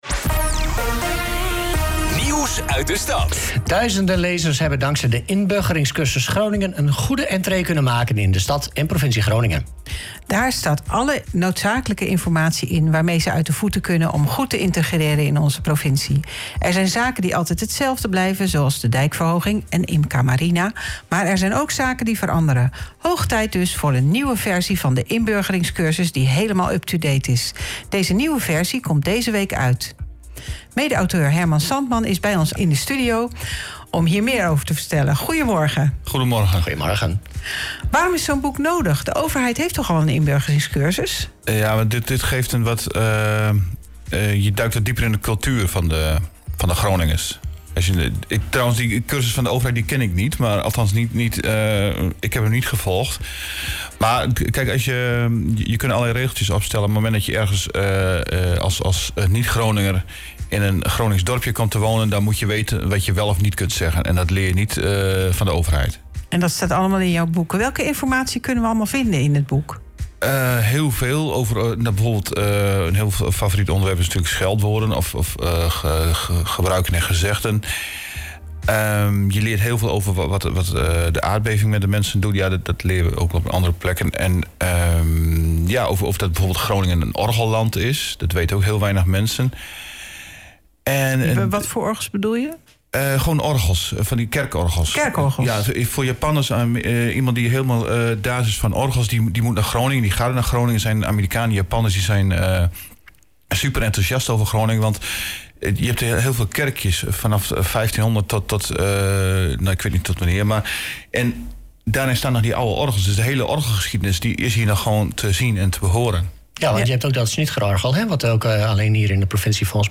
OOG-Ochtendshow-interview-over-Inburgeringscursus-Groningen-2.0.mp3